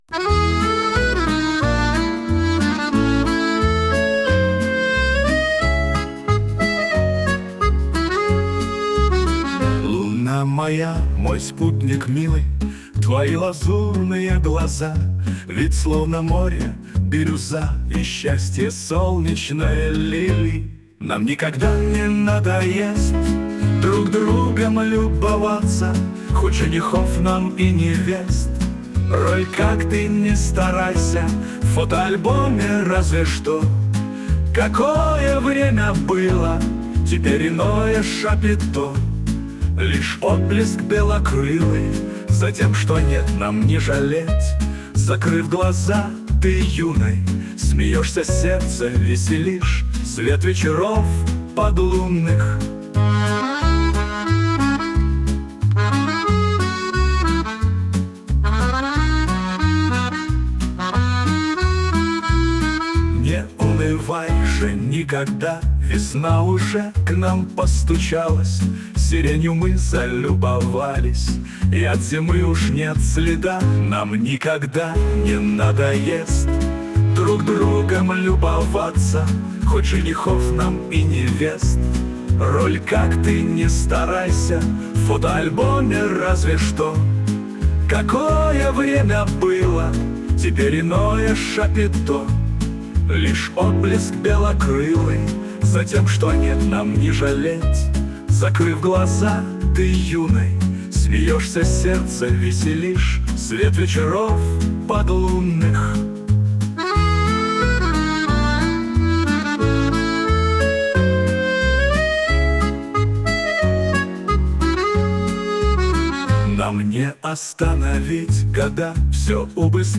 Мелодия на слова песни:
СТИЛЬОВІ ЖАНРИ: Ліричний